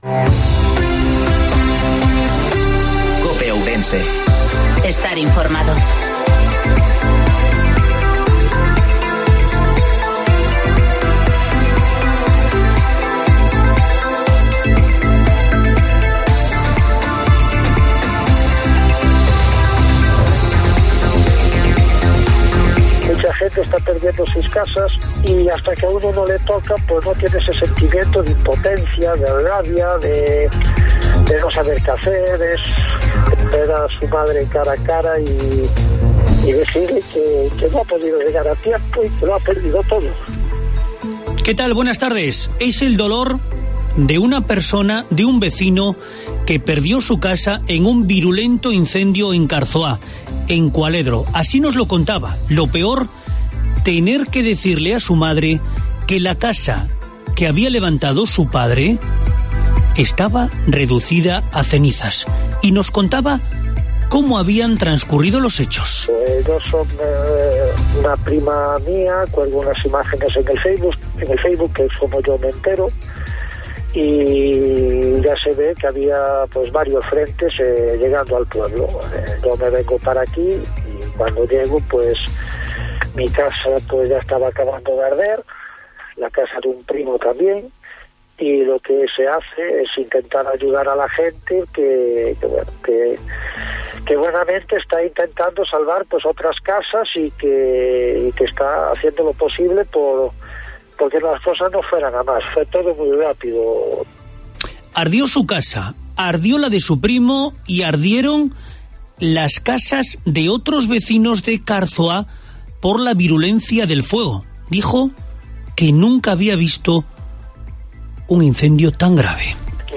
INFORMATIVO MEDIODIA COPE OURENSE